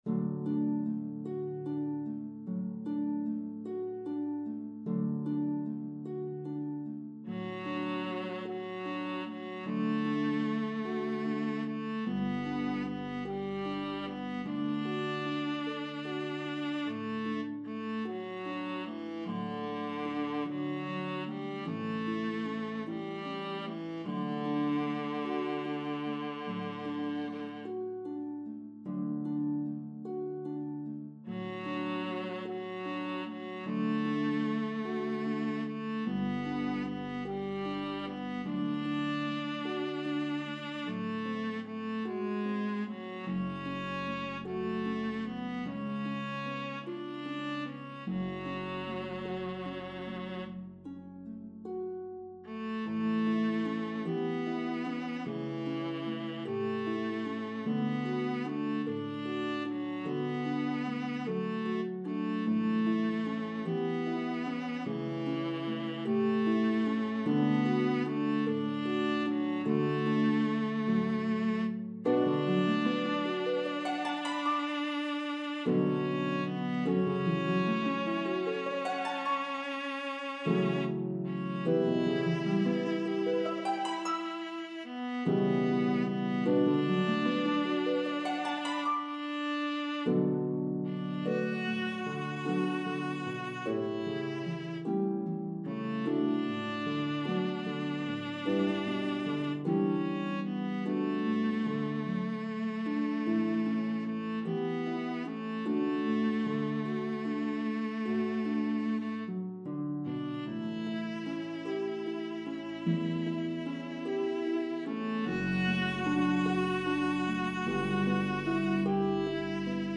The melody of the two verses varies in rhythm & pitches.